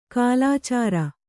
♪ kālācāra